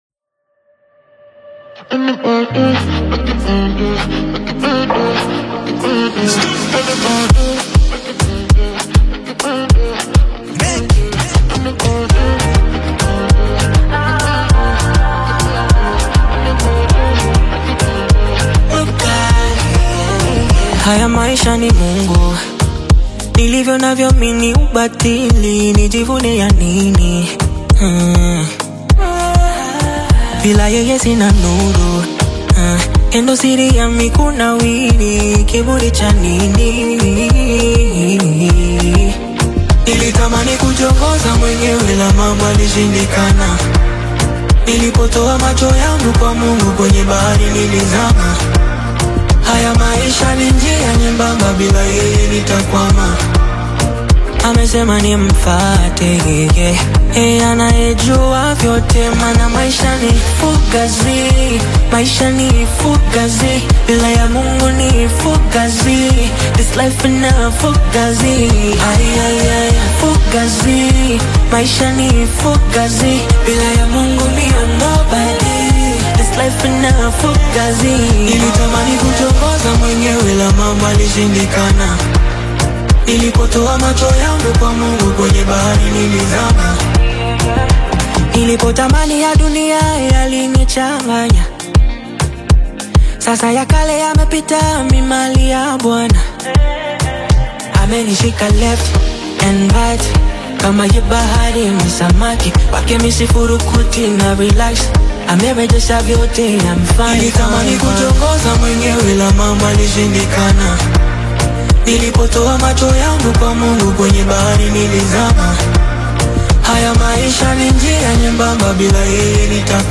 powerful vocals and intricate instrumentation